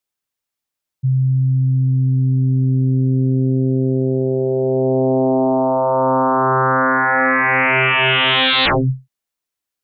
FM-Synthese Sweep
FM-Synthese+Sweep.mp3